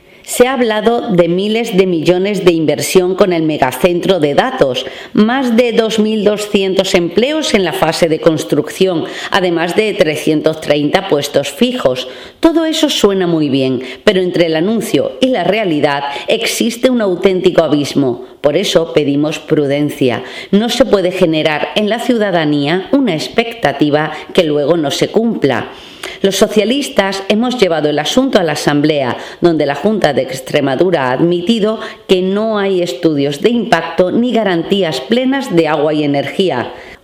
CORTE DE AUDIO de Silvia González: